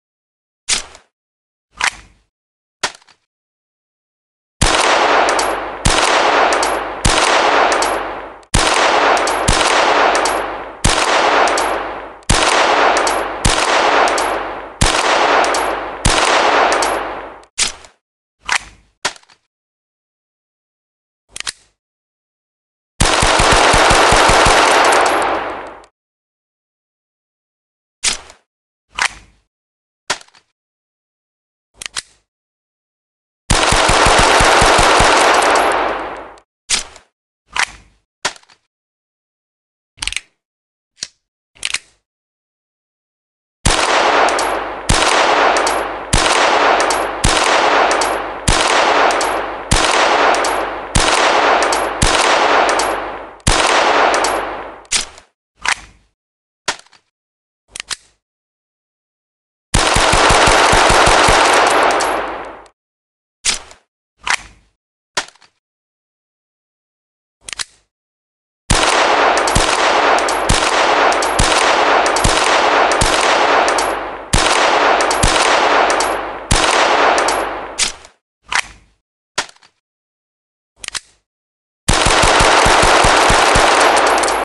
Tiếng Súng Lục bắn đạn lẻ và liên thanh
Thể loại: Đánh nhau, vũ khí
Âm thanh thường bắt đầu bằng tiếng kim hỏa khô, tiếp theo là tiếng nổ sắc, gọn và vang dội, tạo cảm giác lực mạnh và tốc độ cao. Với đạn lẻ, mỗi phát tách biệt, dứt khoát, có độ vang ngắn. Với liên thanh, nhịp bắn nhanh, dồn dập, tạo chuỗi âm thanh căng thẳng, bắn nhau.
tieng-sung-luc-ban-dan-le-va-lien-thanh-www_tiengdong_com.mp3